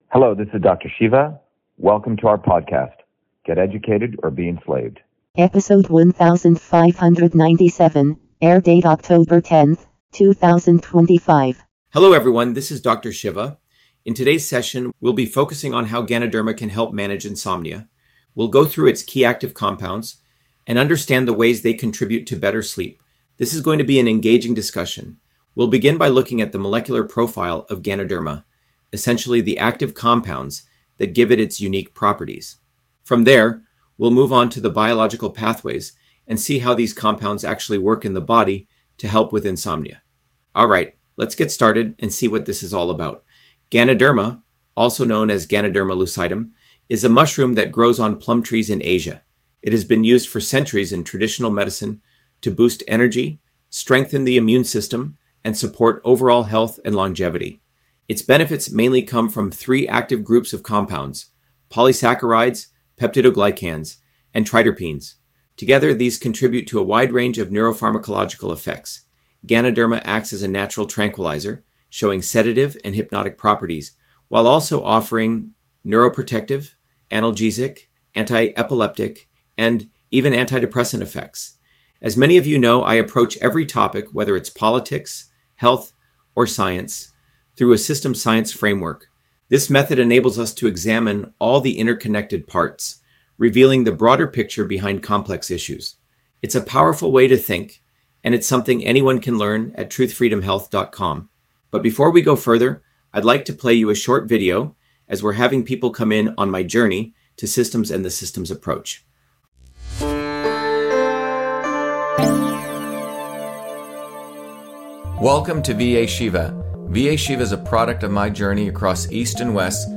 In this interview, Dr.SHIVA Ayyadurai, MIT PhD, Inventor of Email, Scientist, Engineer and Candidate for President, Talks about Ganoderma on Insomnia: A Whole Systems Approach